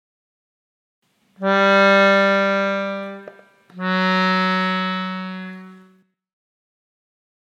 • Bemol (b): baixa medio ton o son.
sol_solbemol.mp3